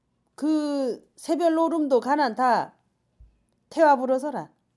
Dialect: Jeju-si